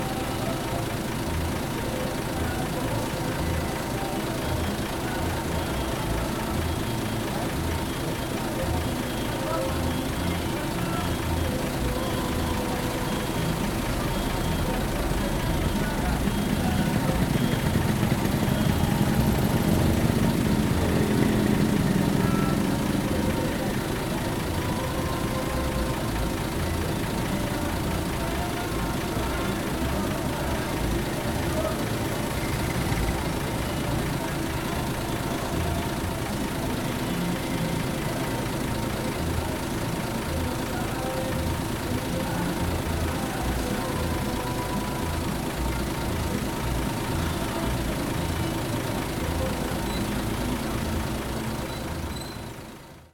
Chiva en la plaza de Riofrío-viernes 5.21pm
Paisaje sonoro de una Chiva en Riofrío, Valle del Cauca.